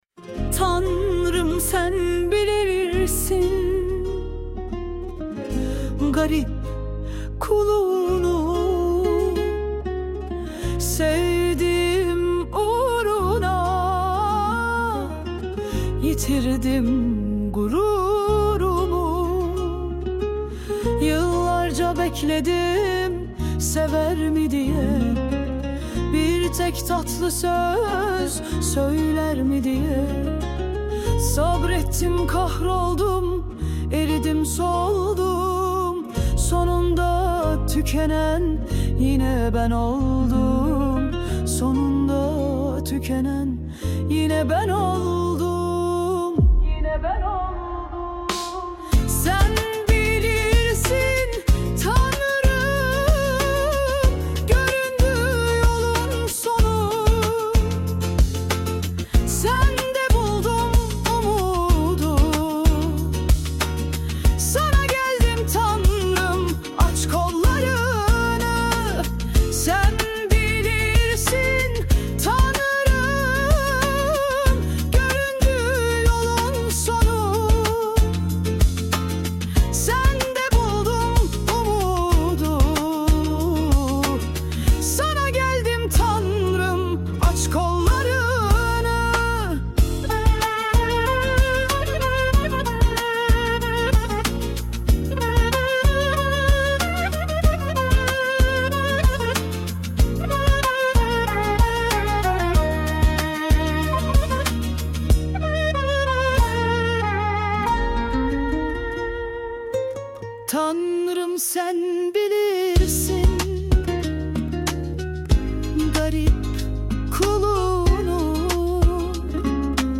Tür : Alaturka Pop